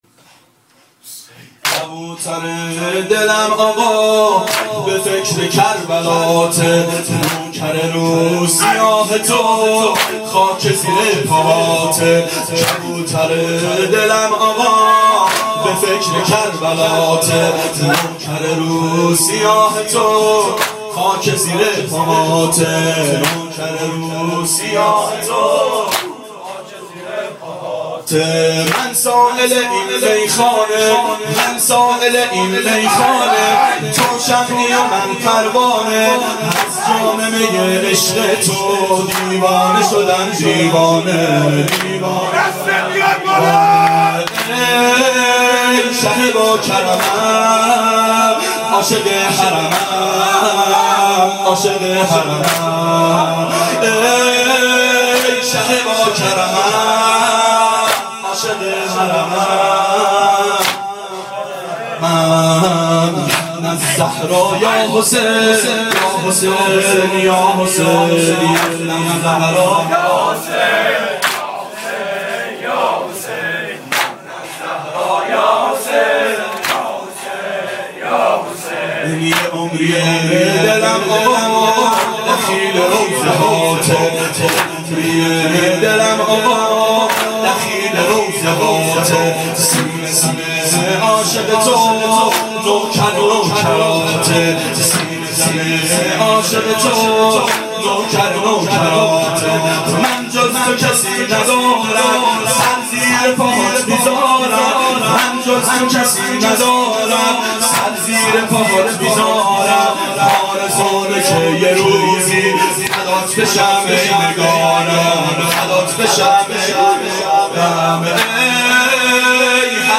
ظهر اربعین سال 1390 محفل شیفتگان حضرت رقیه سلام الله علیها